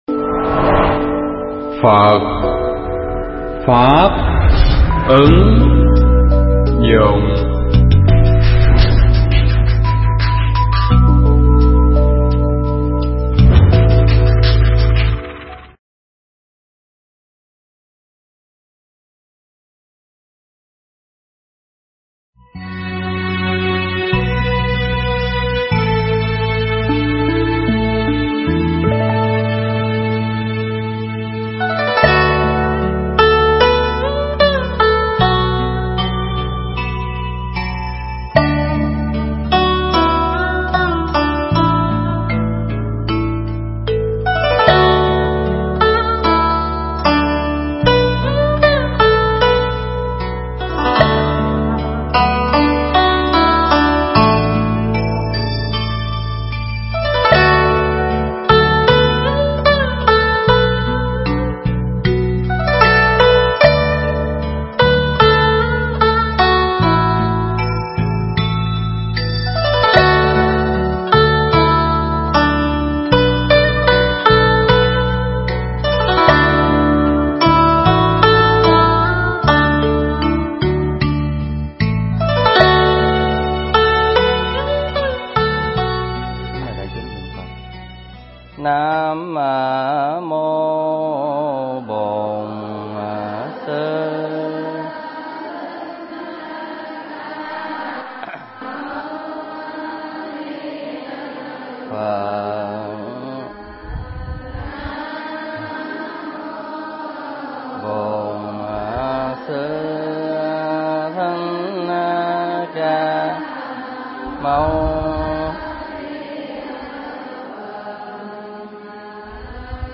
Mp3 pháp thoại Kinh Trung Bộ
thuyết pháp tại chùa Pháp Hải – TP.HCM ngày 02 tháng 07 năm 2012